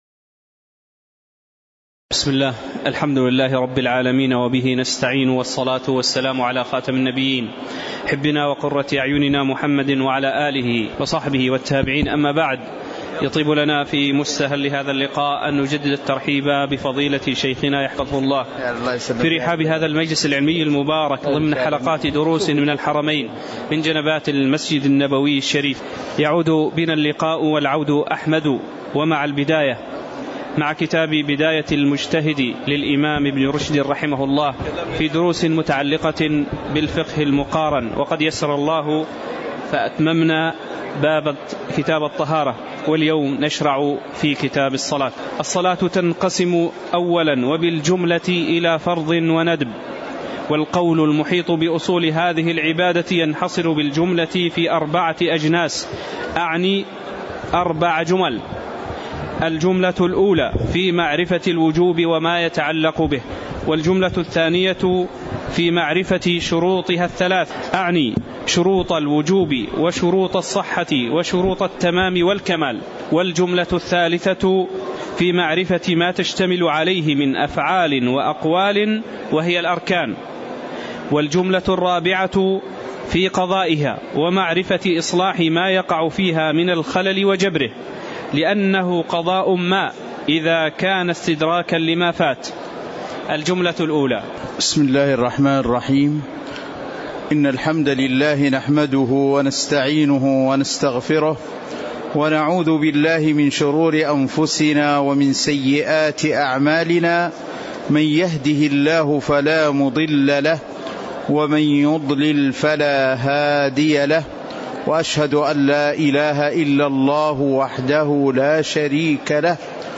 تاريخ النشر ١٦ محرم ١٤٤١ هـ المكان: المسجد النبوي الشيخ